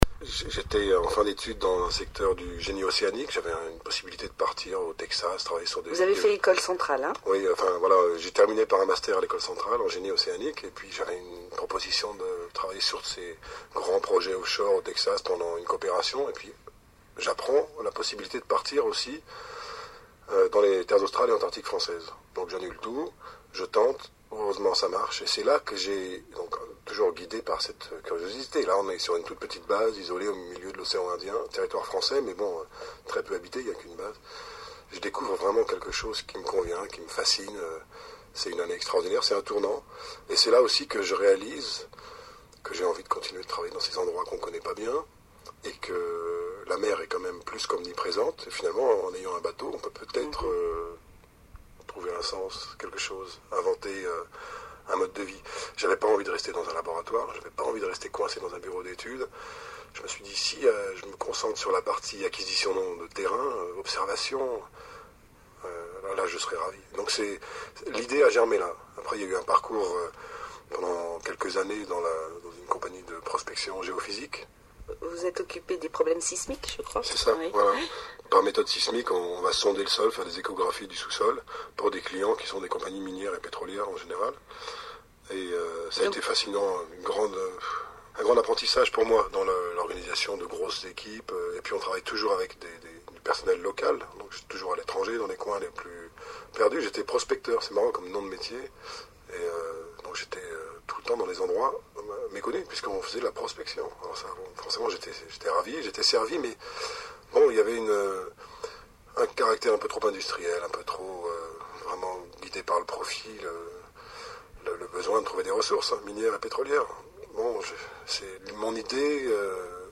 Mais à l’oral, très souvent, vous entendrez que on, avec les deux mots prononcés bien distinctement.